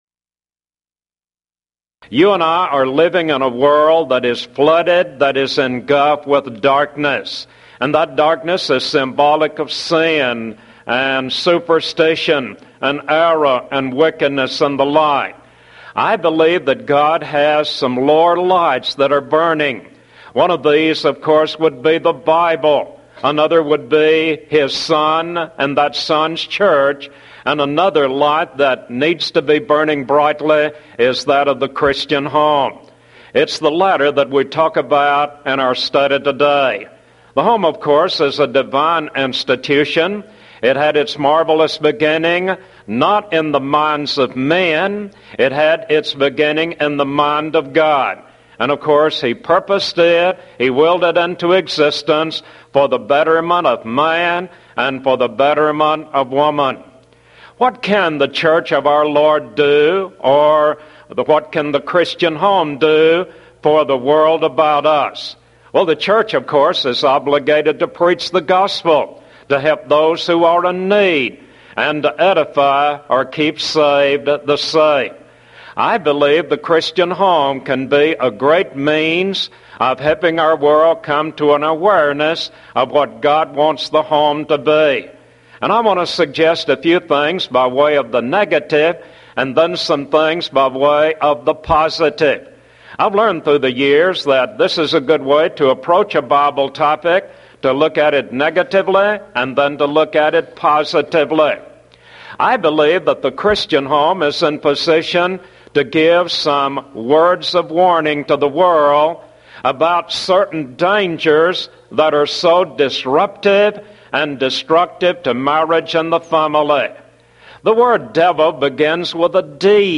Event: 1993 Mid-West Lectures Theme/Title: The Christian Family
lecture